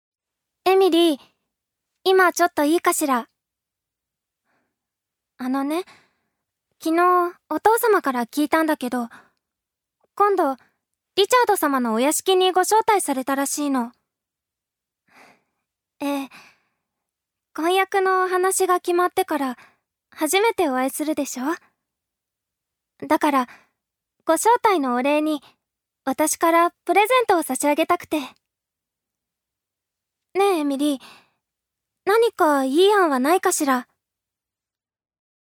ジュニア：女性
セリフ２